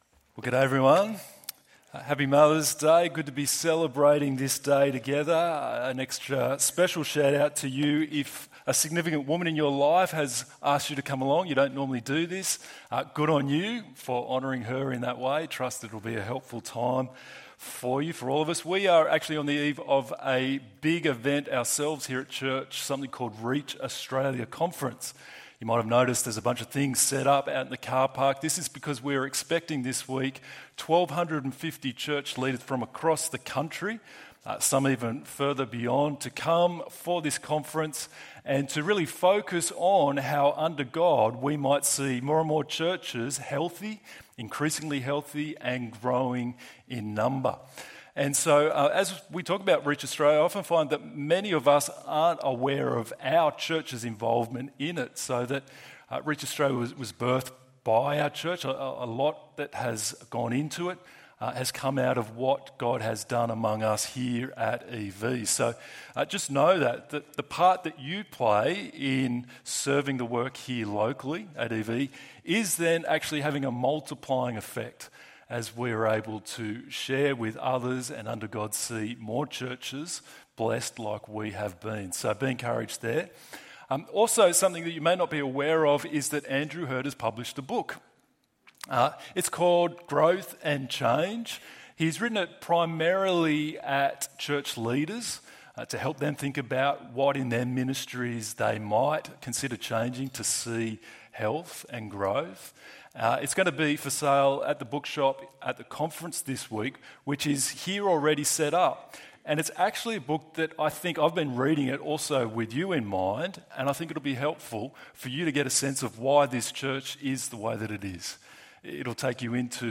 I Was Shown Mercy ~ EV Church Sermons Podcast